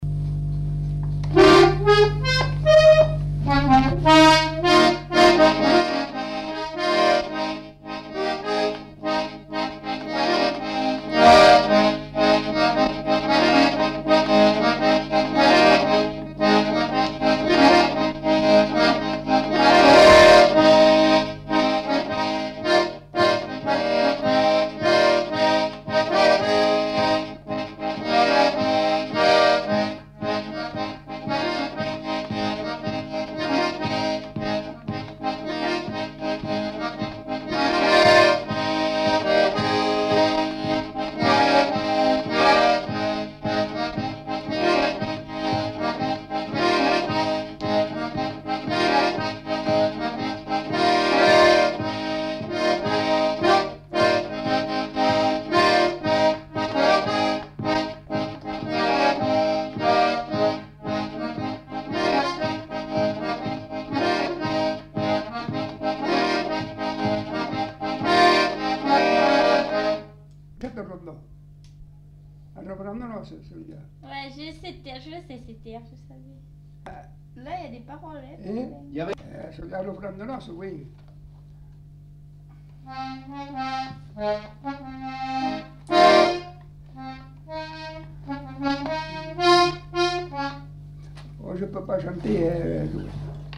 Aire culturelle : Savès
Lieu : Pompiac
Genre : morceau instrumental
Instrument de musique : accordéon diatonique
Danse : rondeau